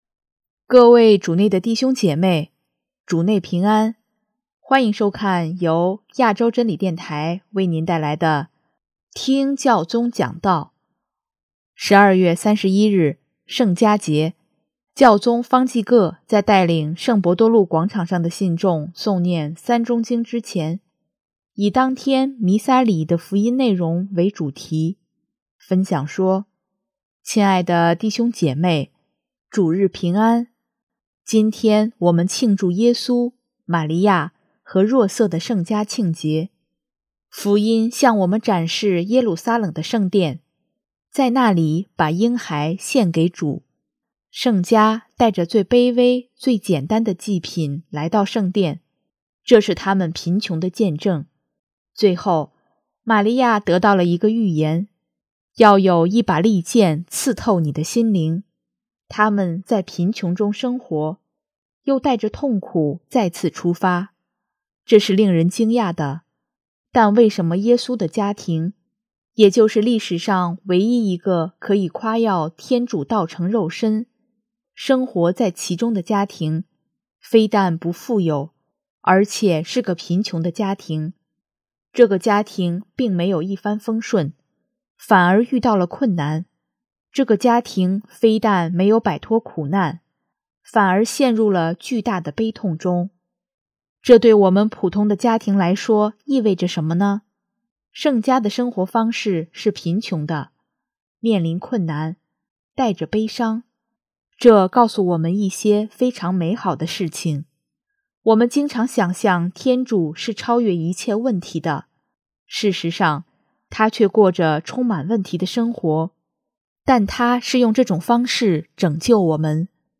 12月31日，圣家节，教宗方济各在带领圣伯多禄广场上的信众诵念《三钟经》之前，以当天弥撒礼仪的福音内容为主题，分享说：